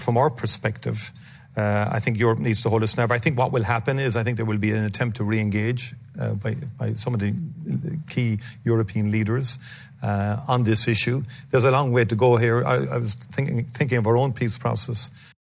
Speaking on The Late Late Show on RTE, Taoiseach Micheál Martin says Europe can’t lose hope: